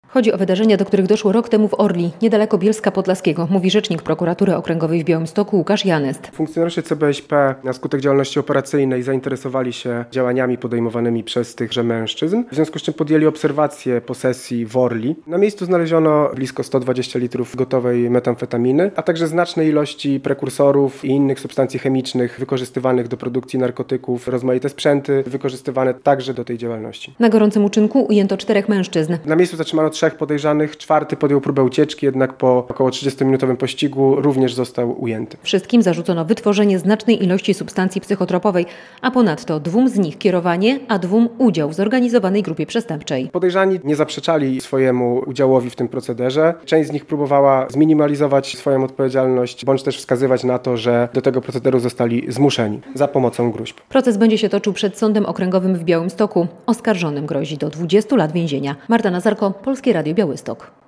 Meksykanie oskarżeni za narkotyki - relacja